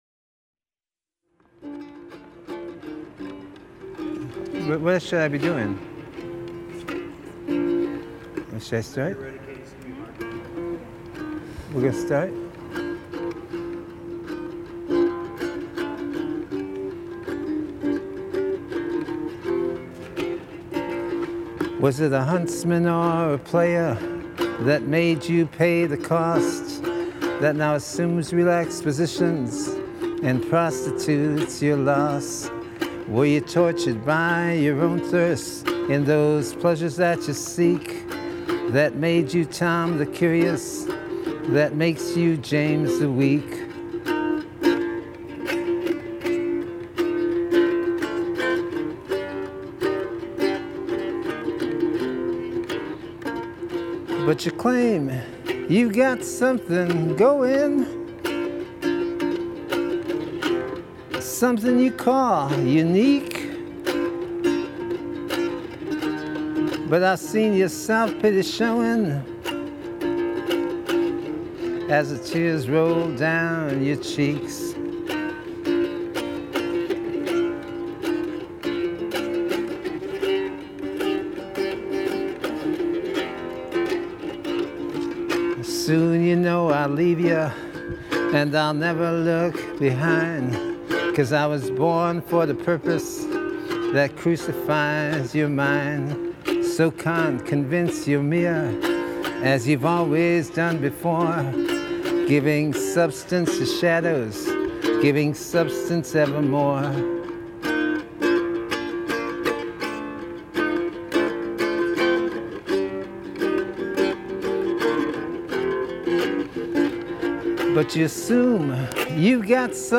Then a shadowy hallway just before the courtyard.